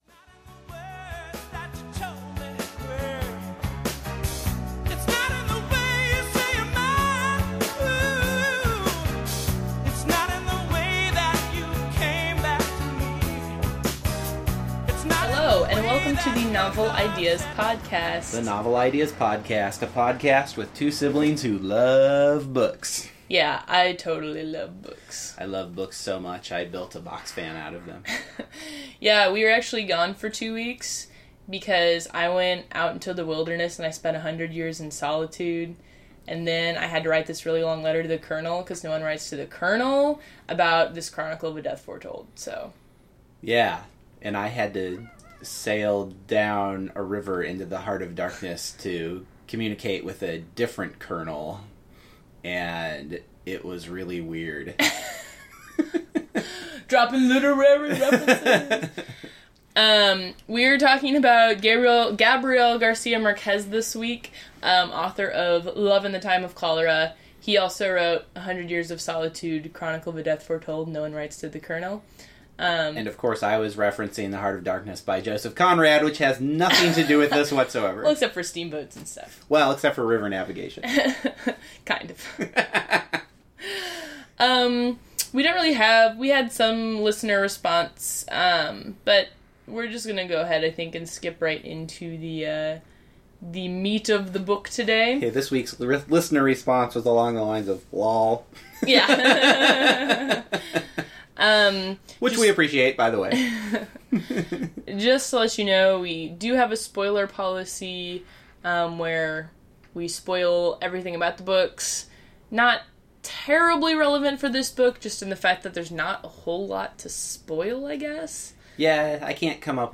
After a week off, your favorite intrepid, book-loving, podcasting siblings have returned.